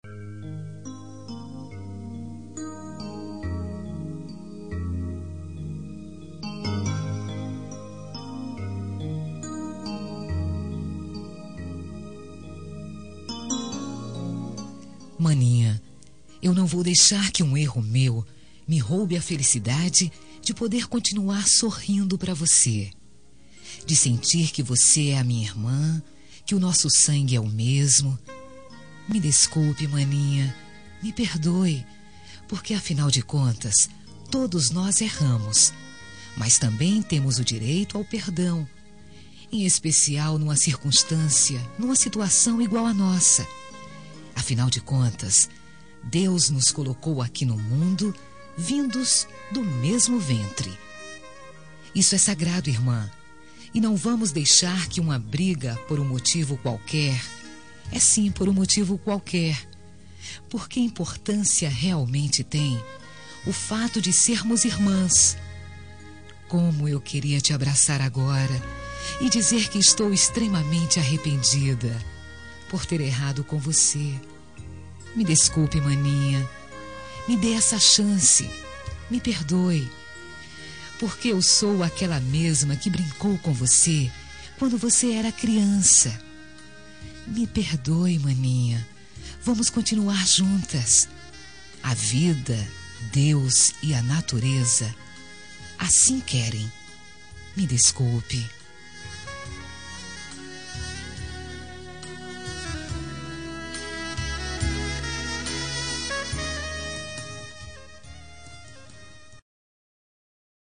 Reconciliação Familiar – Voz Feminina – Cód: 088729 – Irmã